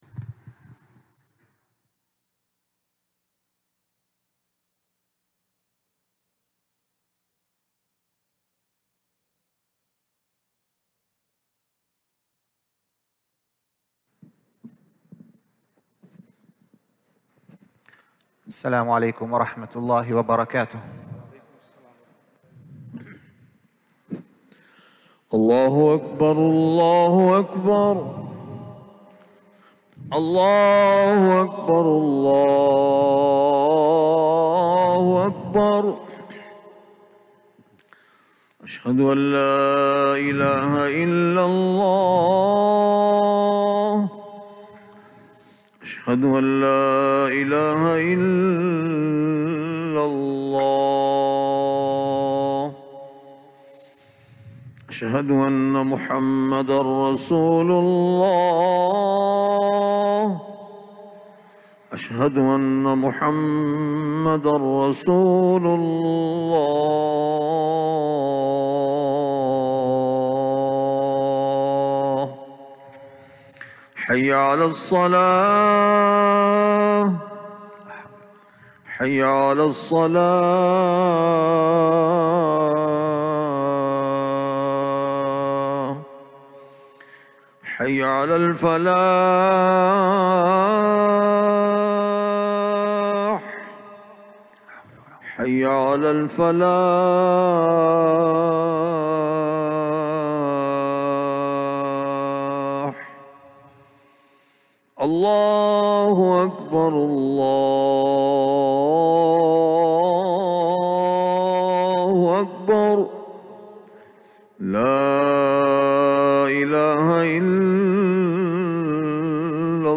Friday Khutbah